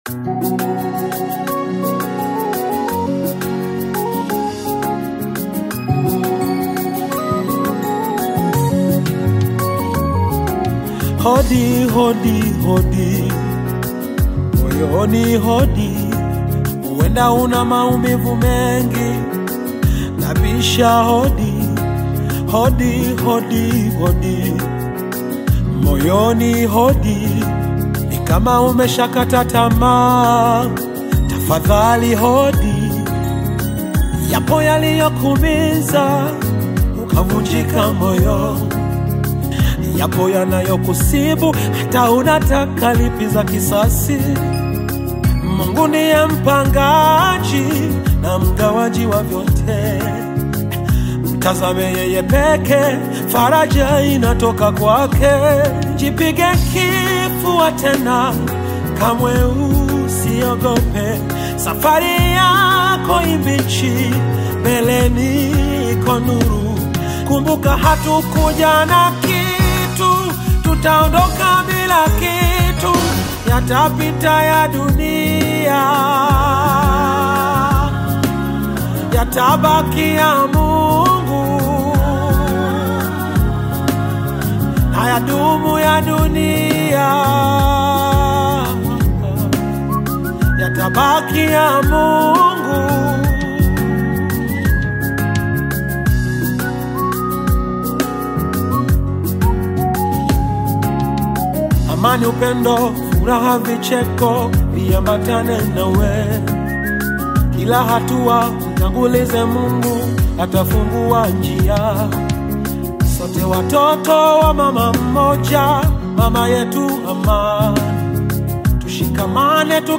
lovely and emotional love song